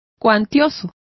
Complete with pronunciation of the translation of numerous.